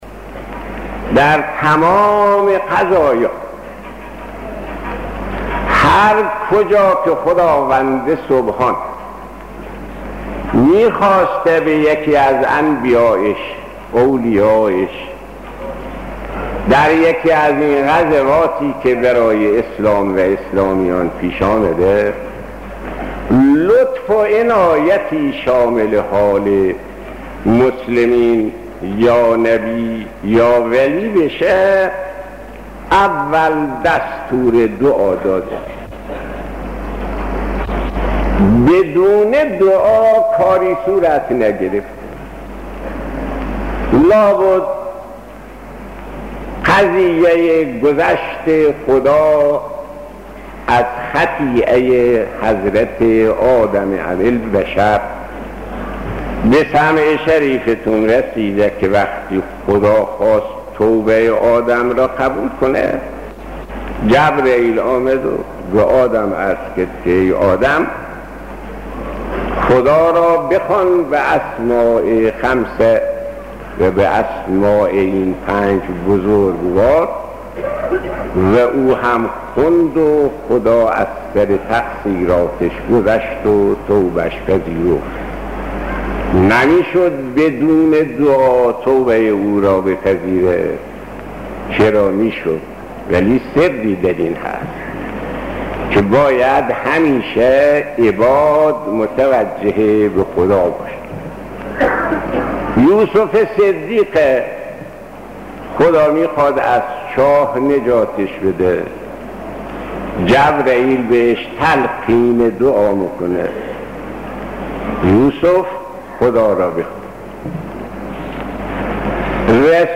صوت/ سخناني از شهيد آيت الله صدوقى پيرامون اهميت دعا در زندگي انسانها